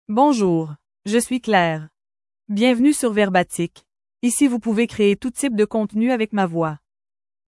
ClaireFemale French AI voice
Claire is a female AI voice for French (Canada).
Voice sample
Listen to Claire's female French voice.
Female
French (Canada)
Claire delivers clear pronunciation with authentic Canada French intonation, making your content sound professionally produced.